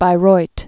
(bī-roit, bīroit)